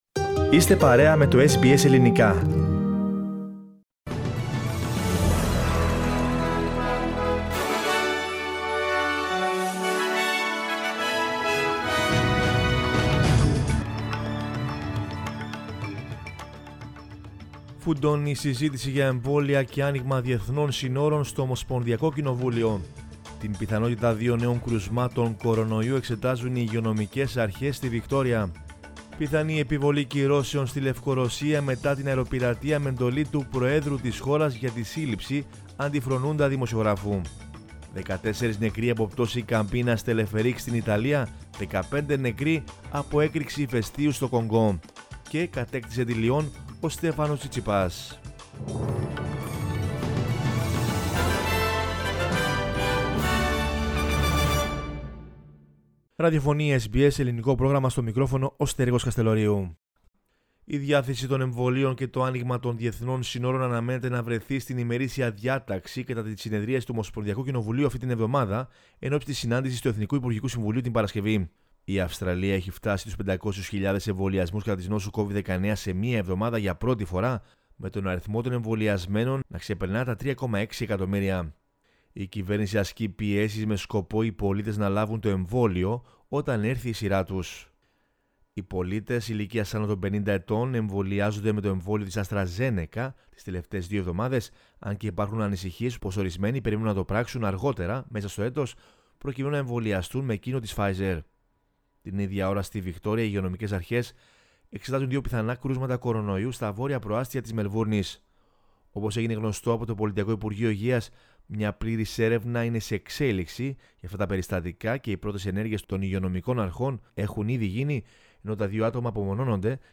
News in Greek from Australia, Greece, Cyprus and the world is the news bulletin of Monday 24 May 2021.